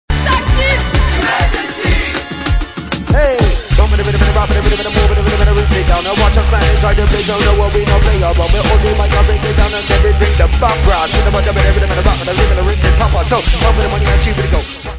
reggae influences diverses